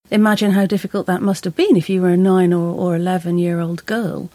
So, again we are left with just a schwa: /ə/.